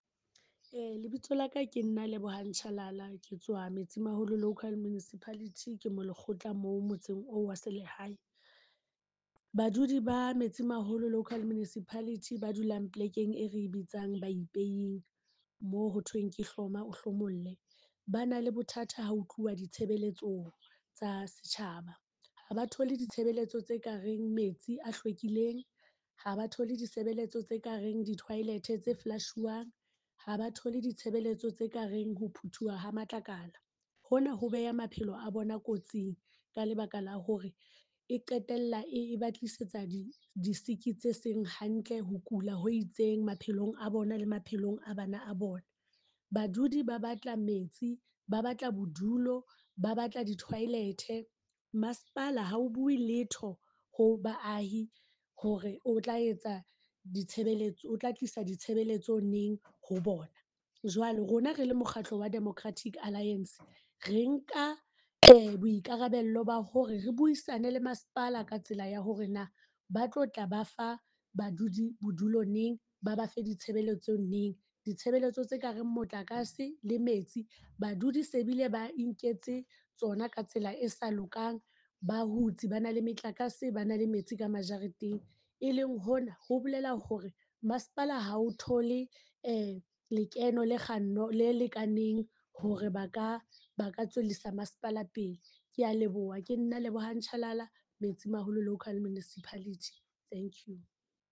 Sesotho soundbite by Cllr Lebohang Chalala.